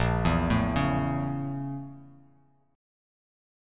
Listen to BbmM7b5 strummed